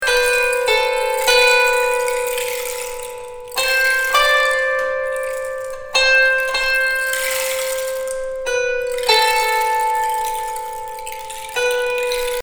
resonance-extrait-7-harpe-et-ruisseau.mp3